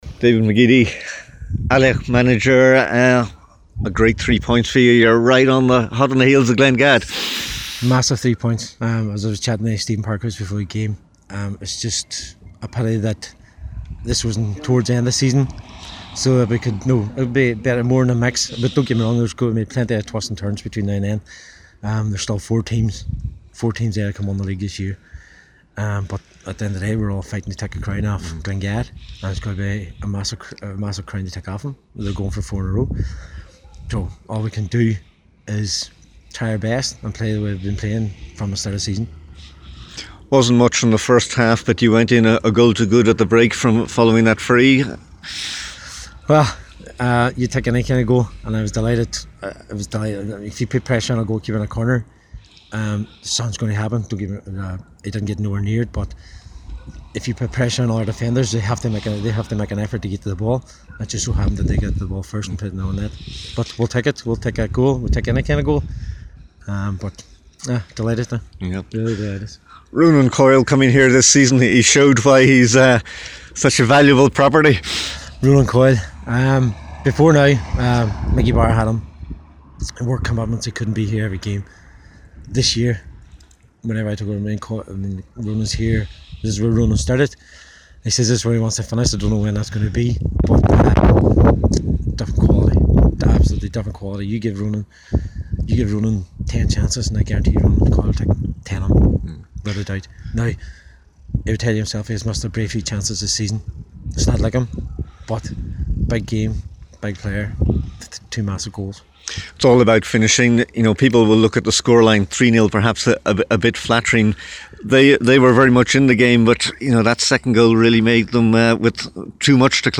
after the match…